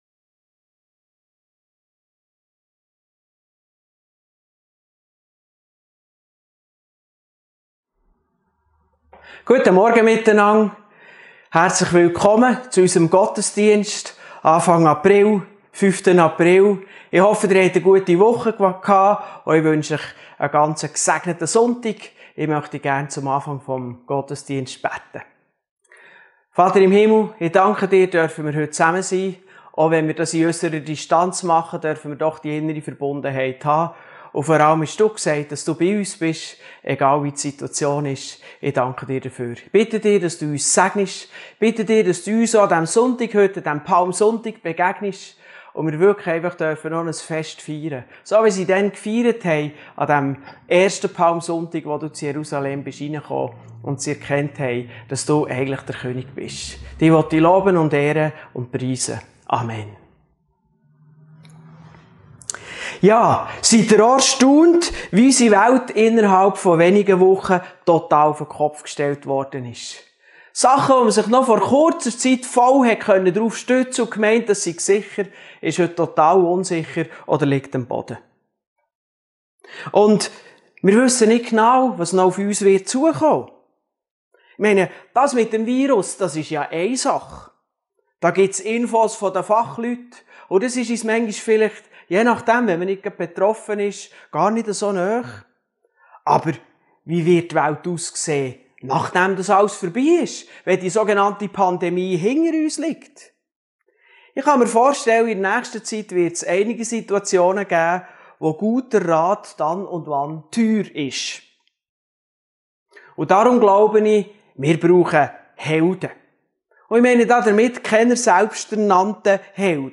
Passage: Lk 5,1-11 Dienstart: Gottesdienst